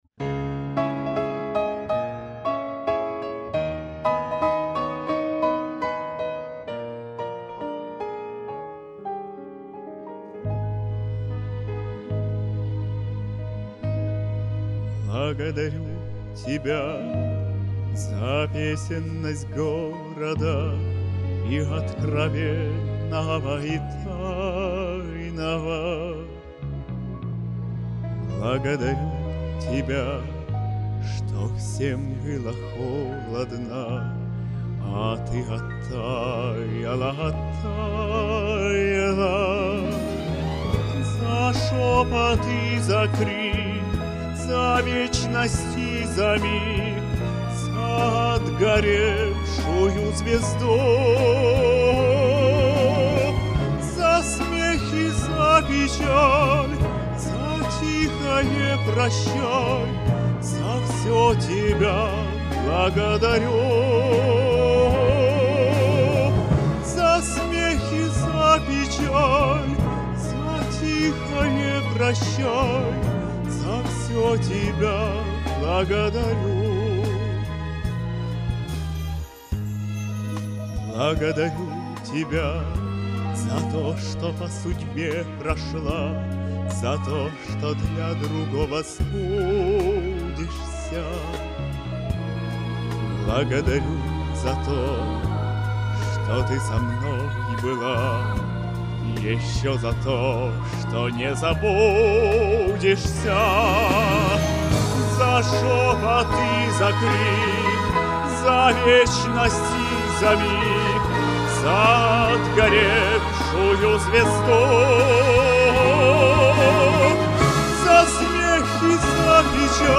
Равноценные прекрасные голоса и исполнения!